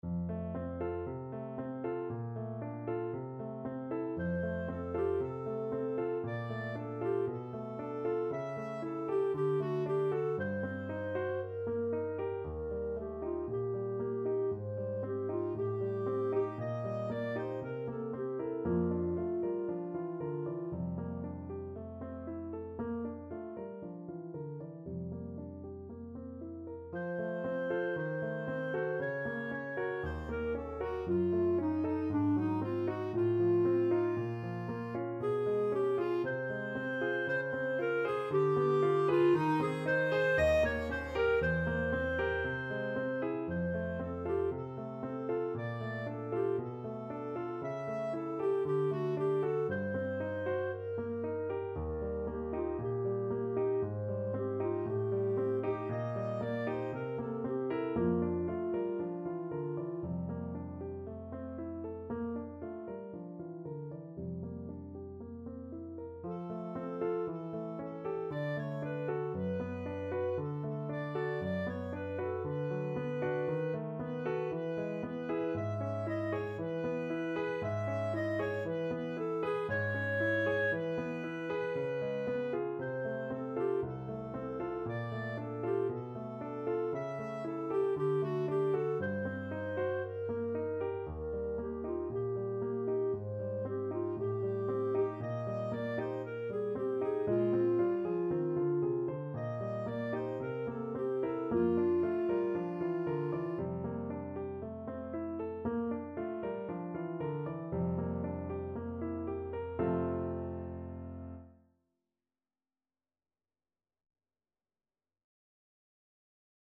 Clarinet
F major (Sounding Pitch) G major (Clarinet in Bb) (View more F major Music for Clarinet )
Allegro moderato =116 (View more music marked Allegro)
Classical (View more Classical Clarinet Music)